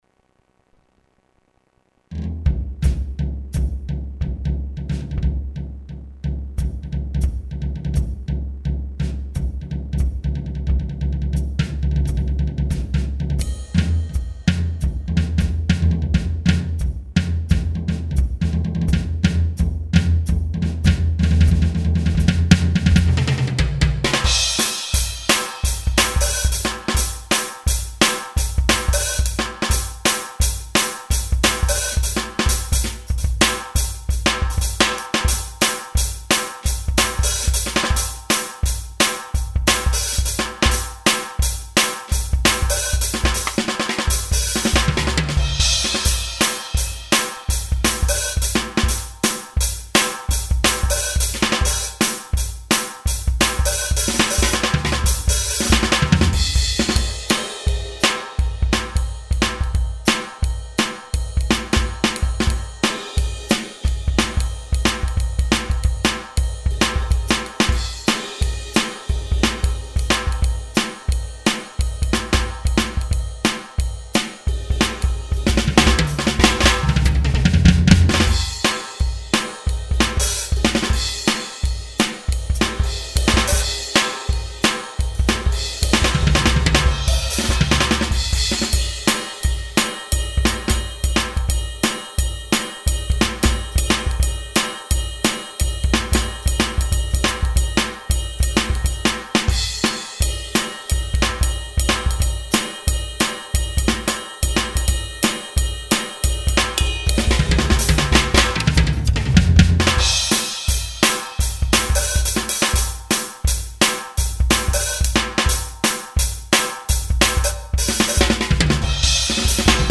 Per registrare i pezzi che qui allego ho usato una recentissima Roland TD12k, con l'aggiunta di un terzo piatto ed un secondo pad da 10 per il timpano.
Perdonatemi quindi se c'è un po' di rumore di fondo, così come qualche errore di esecuzione (ma è bello così! Non vorrete certo ascoltare una batteria artificiale o dei pezzi ritoccati!)
Questo primo pezzo l'ho dedicato all'orso Baloo, per il ritmo stentato, compassato, sincopato, mezzo hard rock, mezzo jazz, che lo caratterizza.
Sono quattro minuti di stranezze: il ritmo viene prima introdotto sui timpani, poi una rullata introduttiva inversa sui tom, e via col ritmo sincopato di cassa rullante.